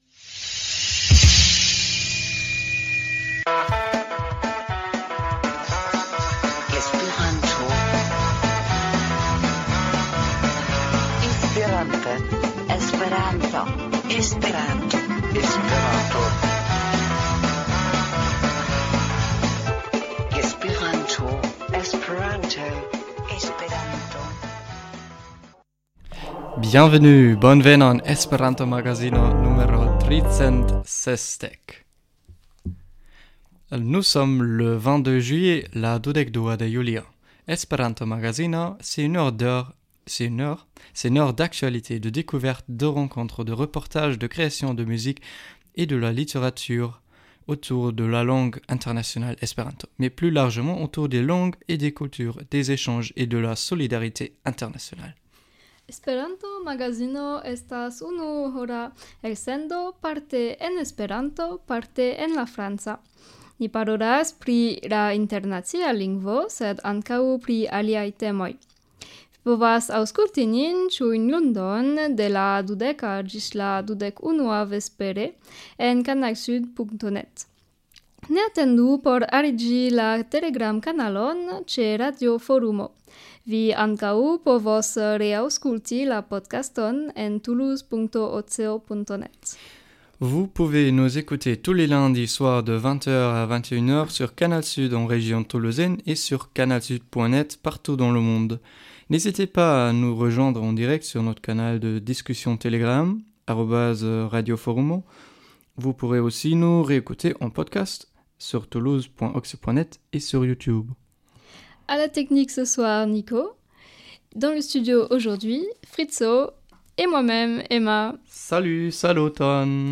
Esperanto-magazino estas unuhora elsendo pri la internacia lingvo sed ne nur, parte Esperante, parte france (kun muziko, anoncoj, raportaĵoj… ).